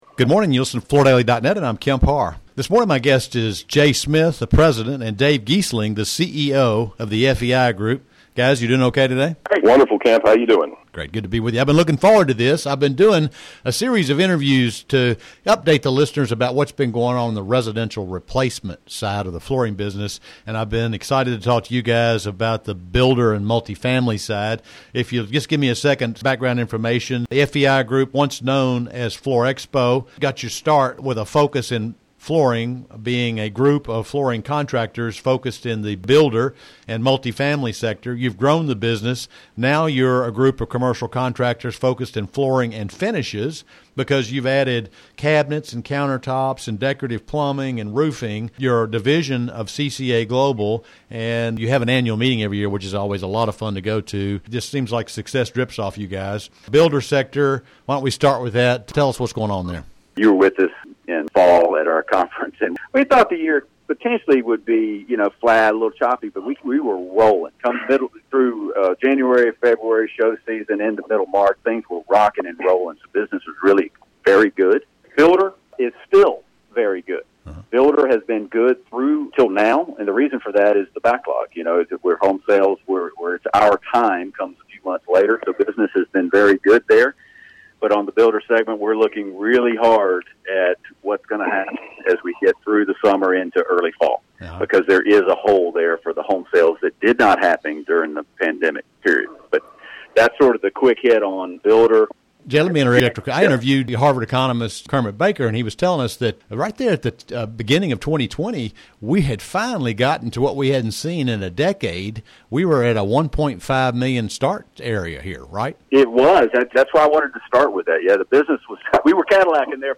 Listen to the interview for more details as well as other news from the group.